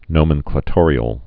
(nōmən-klə-tôrē-əl)